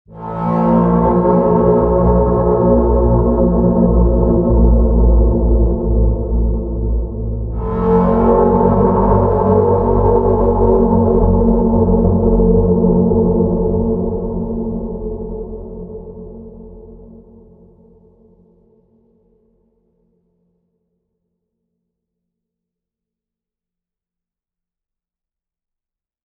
zvuk-iz-pod-zemli-zovuschiy-za-soboy
• Категория: Страшные звуки
• Качество: Высокое